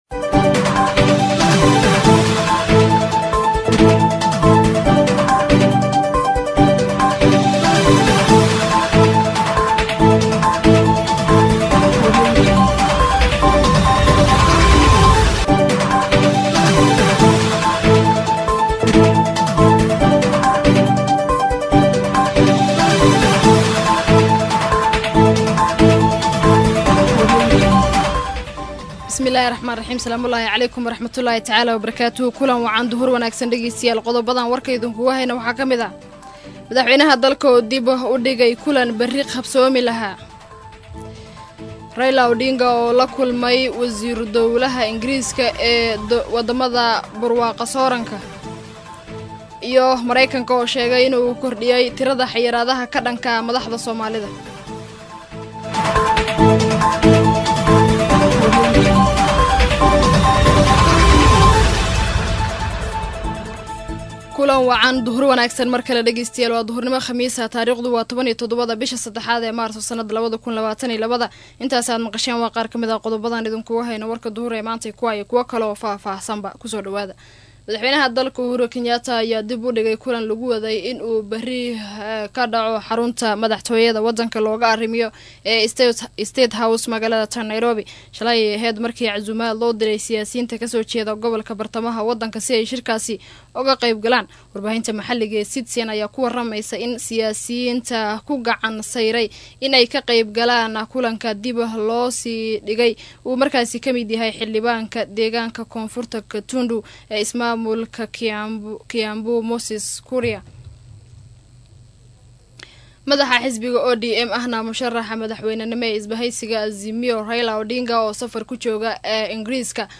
DHAGEYSO:WARKA DUHURNIMO EE IDAACADDA STAR FM